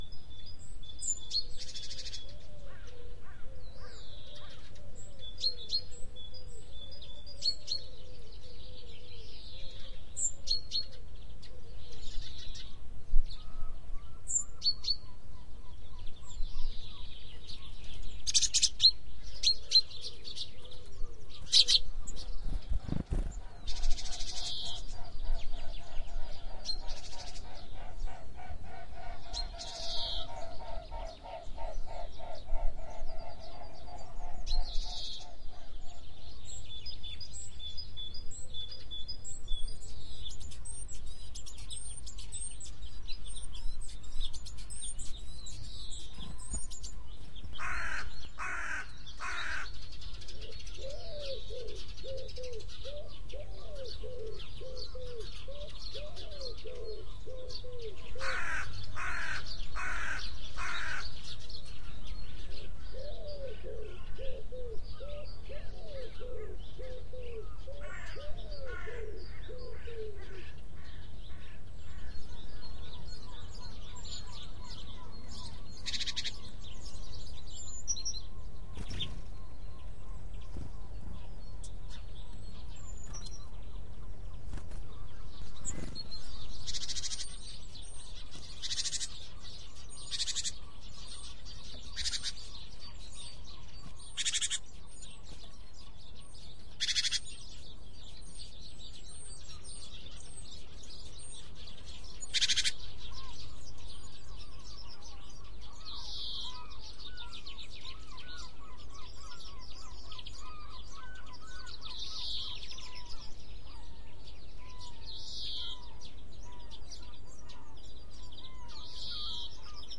鸟鸣 " 鸟鸣三月
描述：博恩霍尔姆岛的鸟鸣。早晨在靠近海边的一些树林边上录制的。
Tag: 鸟类 鸟鸣 沿海 林地